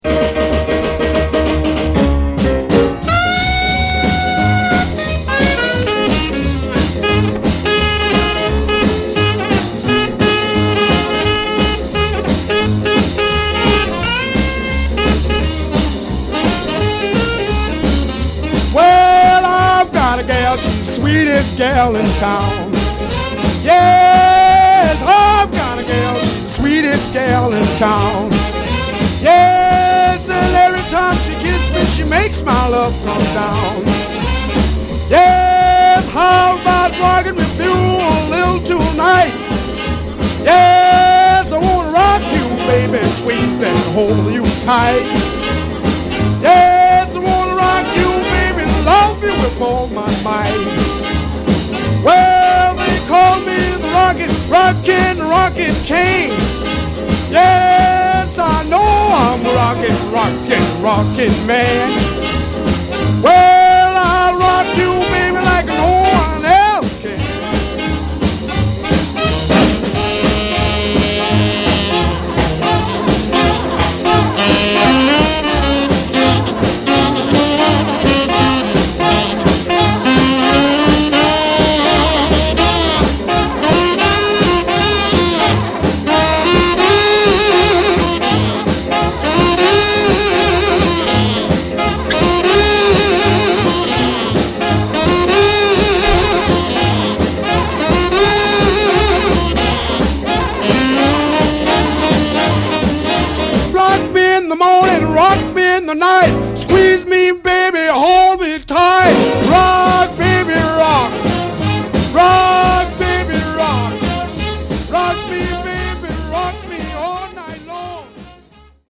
Awesum R&B riddems all night long!!